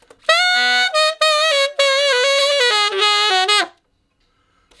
068 Ten Sax Straight (Ab) 06.wav